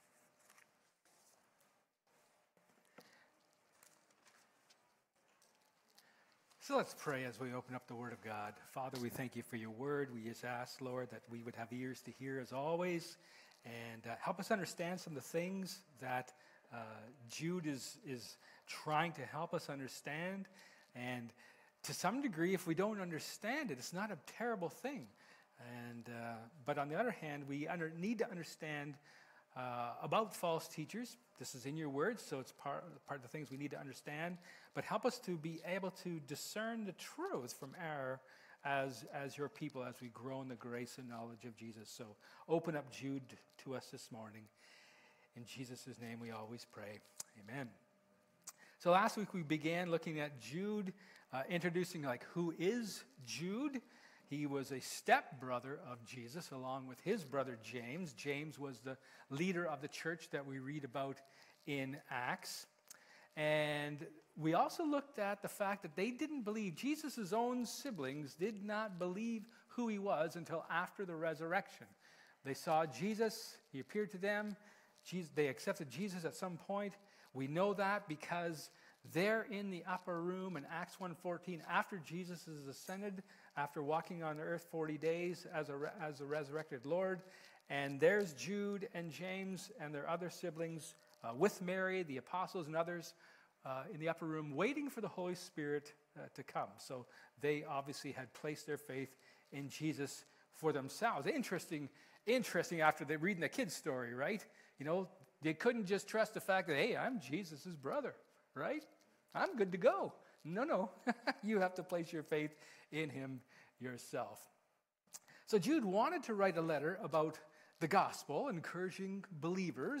Jude 3-7 Service Type: Sermon Jude begins to state why he wrote the letter